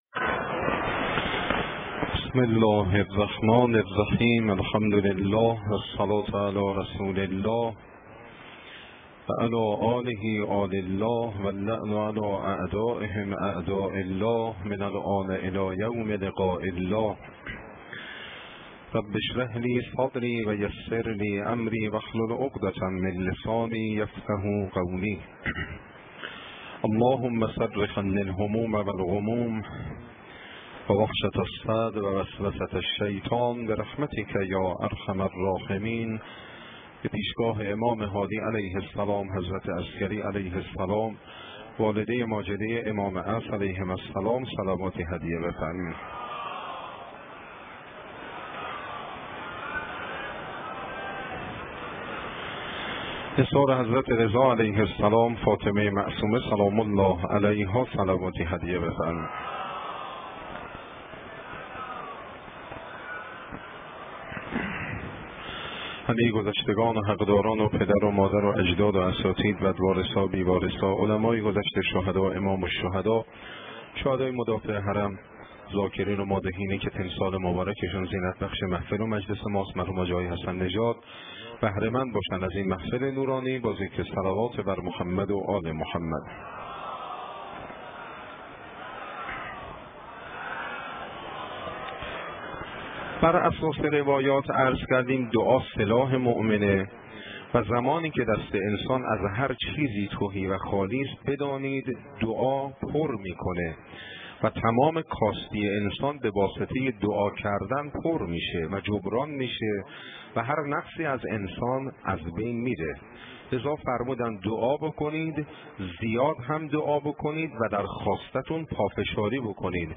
شب 17 ماه رمضان - بیت آیت الله بروجردی
سخنرانی